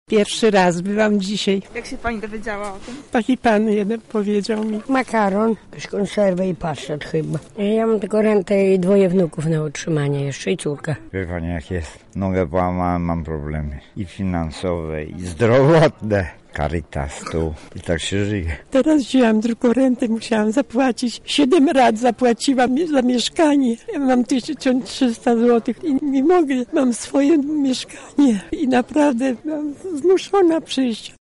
Jadłodajnię przy Kuchni Brata Alberta odwiedziła nasza reporterka:
sonda